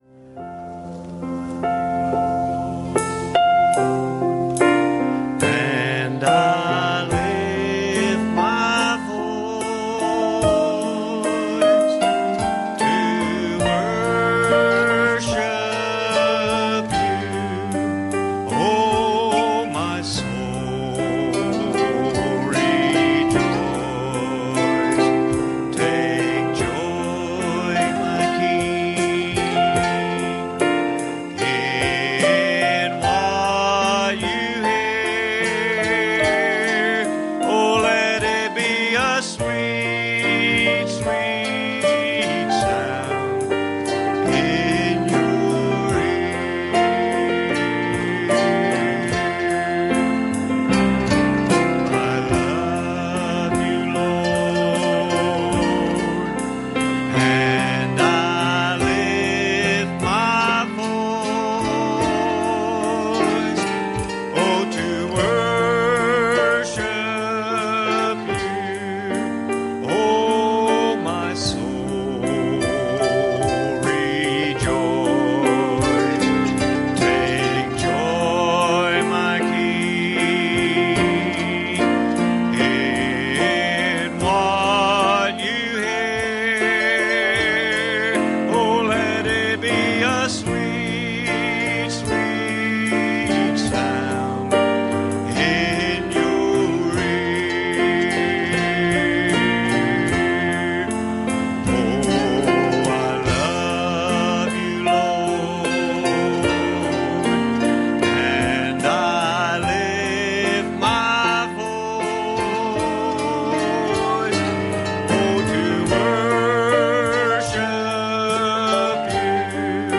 Passage: 1 Kings 18:21 Service Type: Wednesday Evening